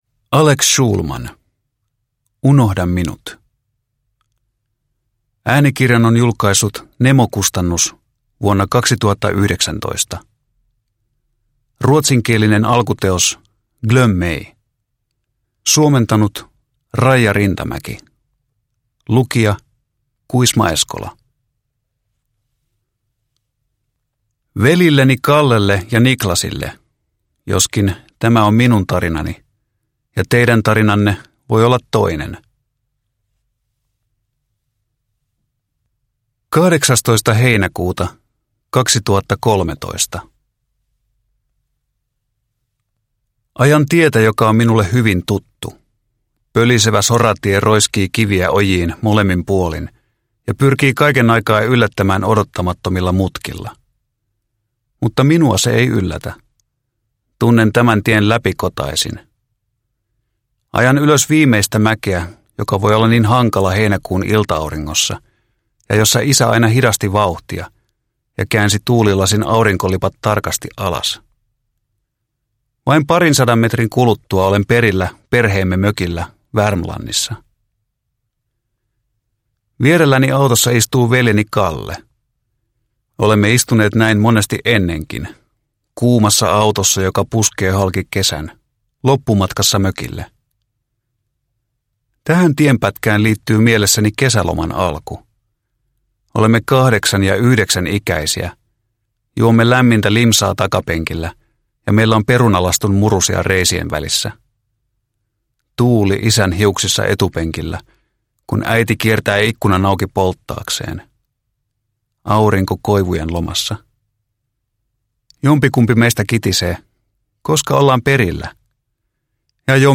Unohda minut – Ljudbok – Laddas ner